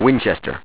1 channel
w2_winchester.wav